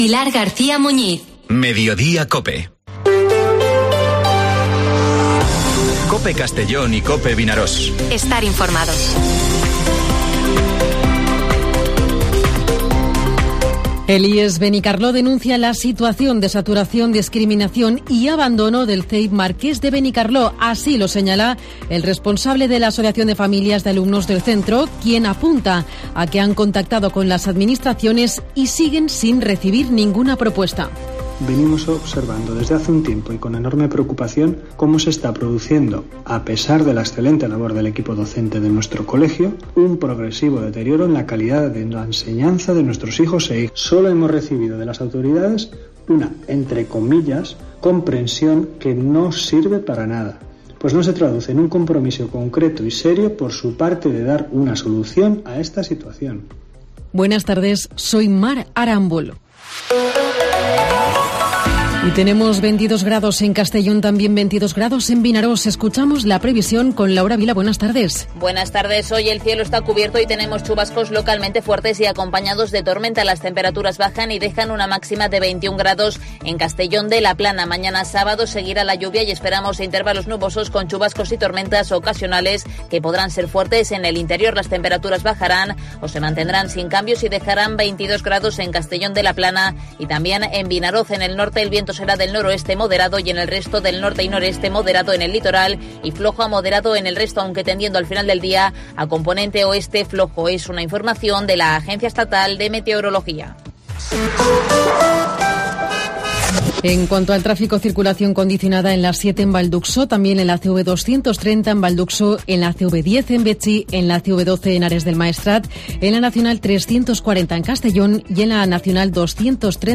Informativo Mediodía COPE en Castellón (12/05/2023)
Carlos Fabra, en una entrevista en exclusiva para Cope, responde a las críticas suscitadas tras su asistencia al acto de presentación de candidatura del PP en Castelón. Primer día de campaña electoral, también primer día de fiestas patronales en Vila-real y día de celebración de sesión plenaria extraordinaria para aprobar que se destinen 58 mil euros a 60n expedientes.